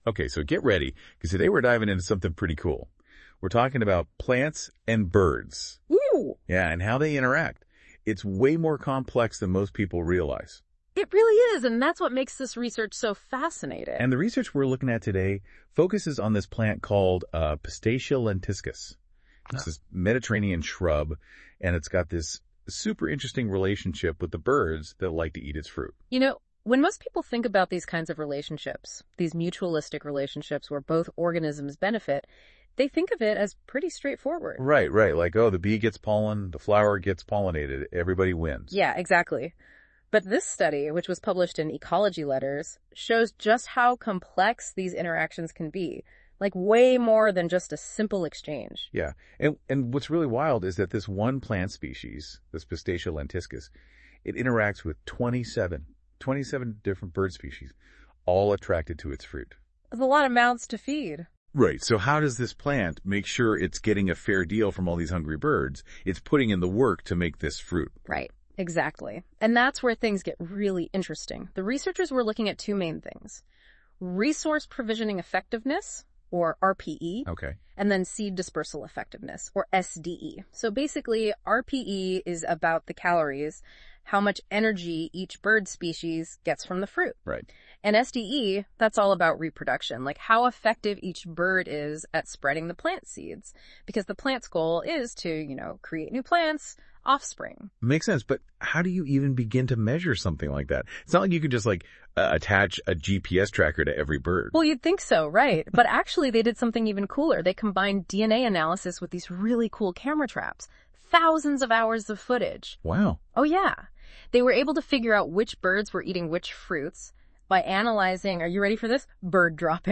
Listen to a short, casual, AI-generated audio summary of the paper